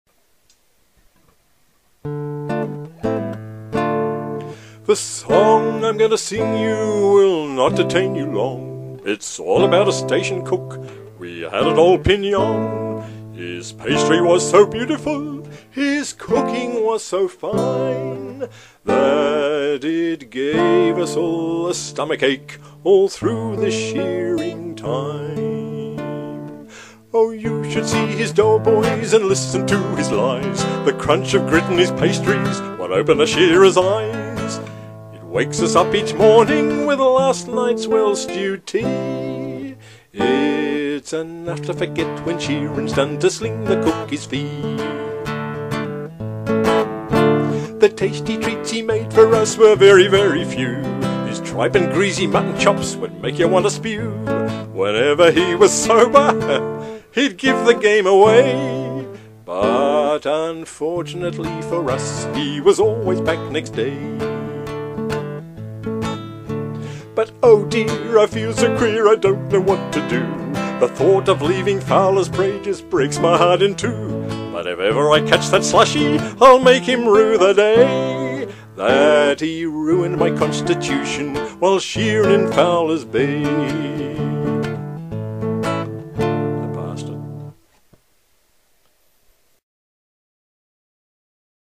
Australian Ballads
Preview 'The Station Cook' ballad